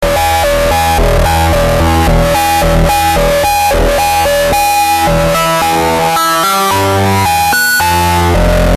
描述：d Vanguard合成器吉他
标签： 110 bpm Heavy Metal Loops Guitar Electric Loops 1.47 MB wav Key : Unknown
声道立体声